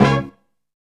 SHARP HIT.wav